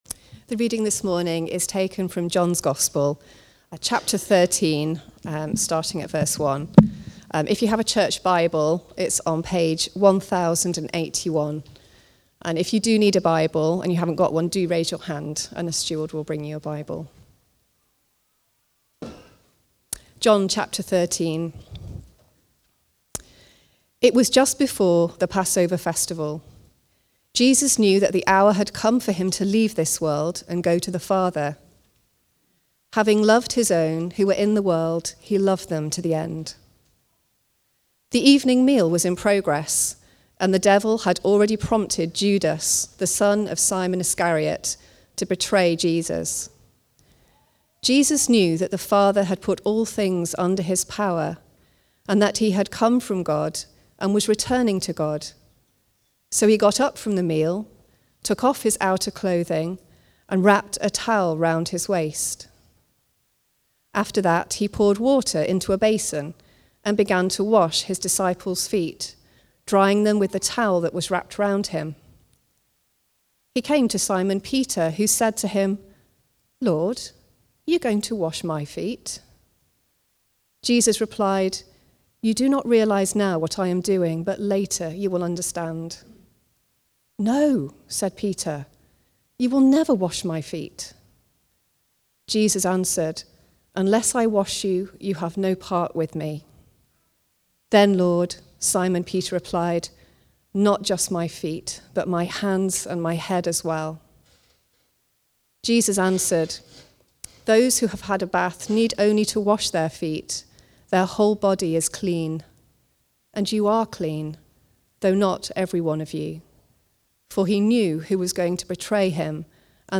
Preaching
The Servant (John 13:1-17) from the series Comfort and Joy. Recorded at Woodstock Road Baptist Church on 19 January 2025.